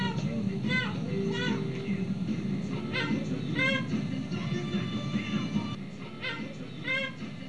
sing, talk and
SING.WAV